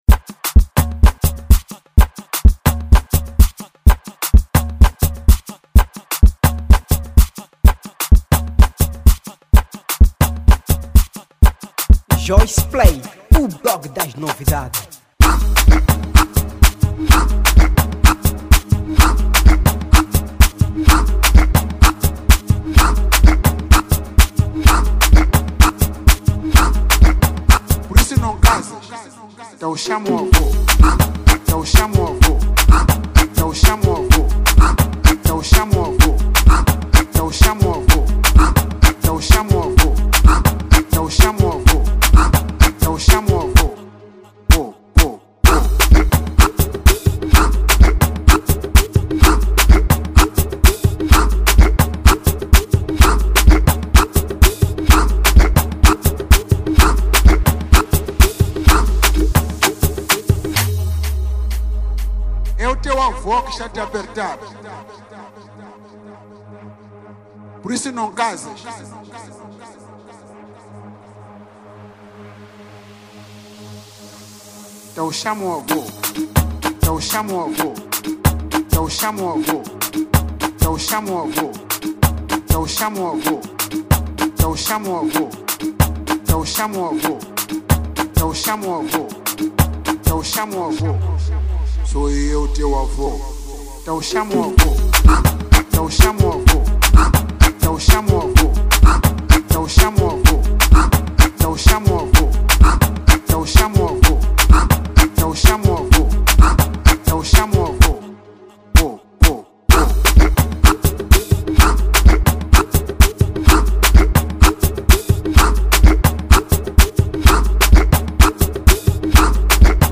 Afro House
Género: Afro House Ano de Lançamento